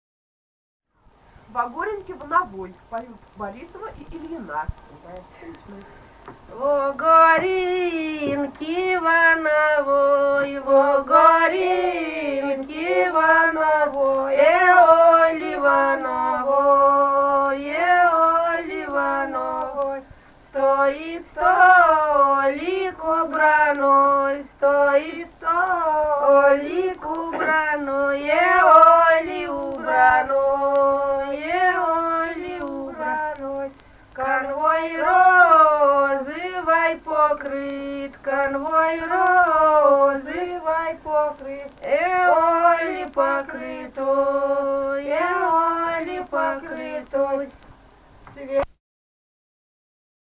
Русские народные песни Владимирской области 15а. Во горенке во новой (свадебная) д. Глебовка Муромского района Владимирской области.